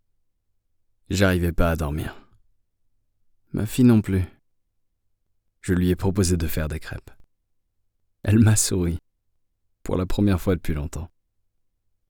MALE VOICE OVER DEMOS AND EXTRACTS
Commercial KVIK